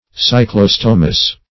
cyclostomous.mp3